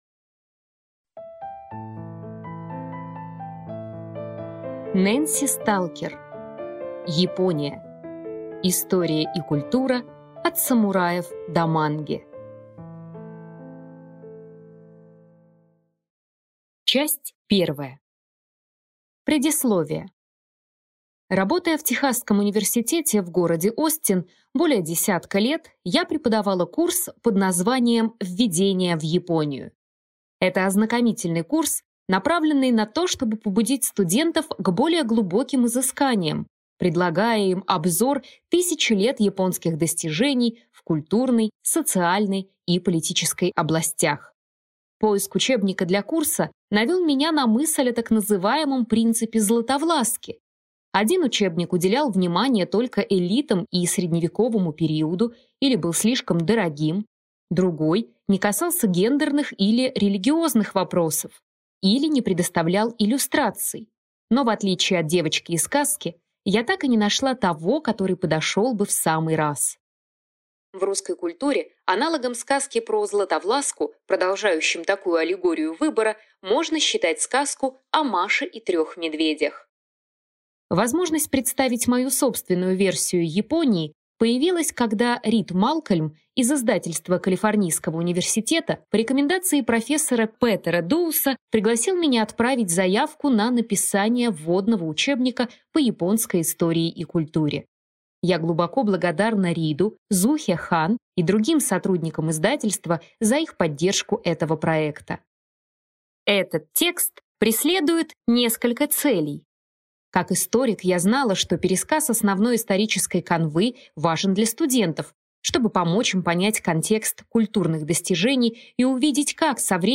Аудиокнига Япония. История и культура: от самураев до манги. Часть 1 | Библиотека аудиокниг